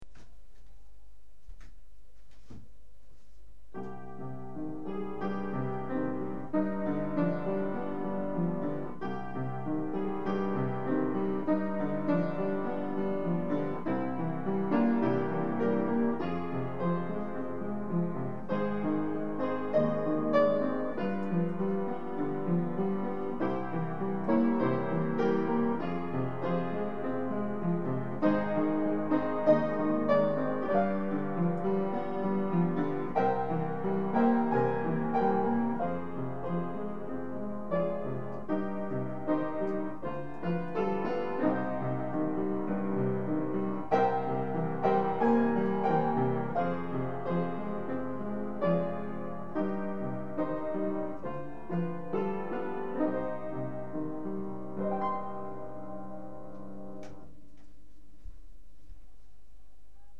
на пианино романс